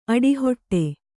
♪ aḍihoṭṭe